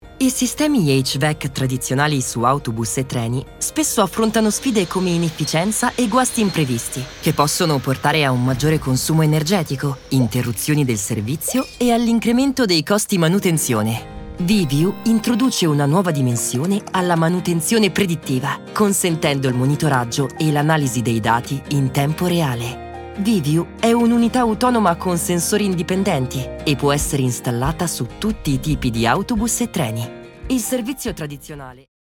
Commercieel, Stoer, Veelzijdig, Vriendelijk, Warm
Audiogids